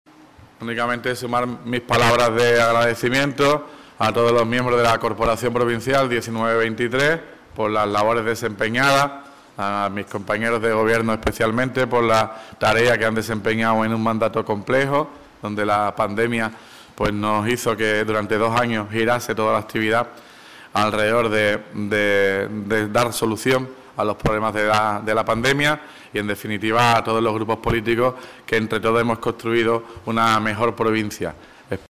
Último Pleno del mandato corporativo 2019-2023
despedida-de-Ruiz-Boix.mp3